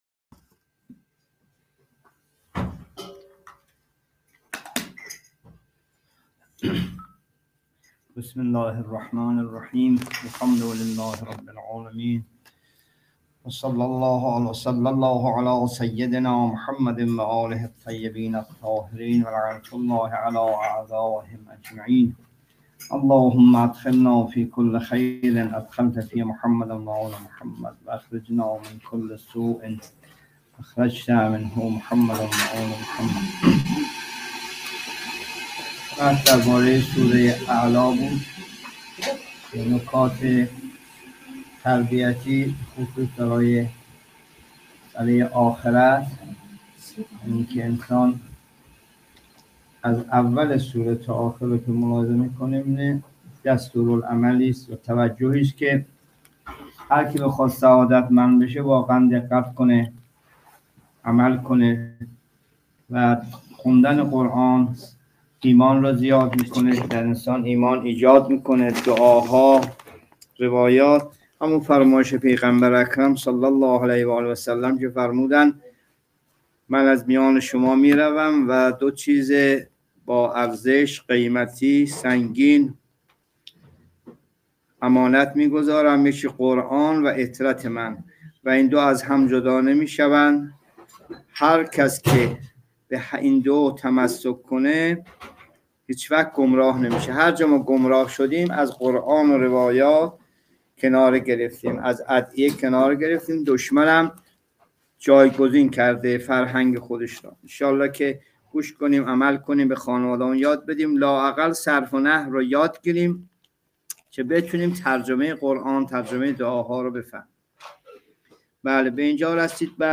جلسه تفسیر قرآن (۱7) سوره اعلی